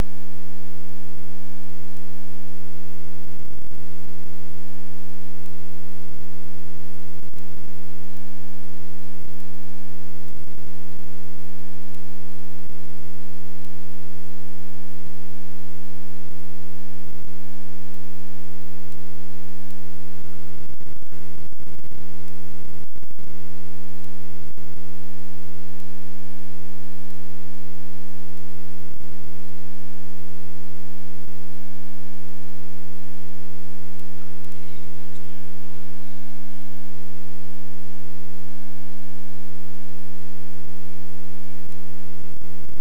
the codec can recorded sound，but the sound is too low，pls checkout our register config，